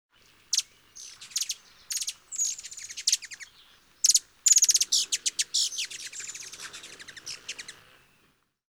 Синебородый украшенный колибри создает необычный звук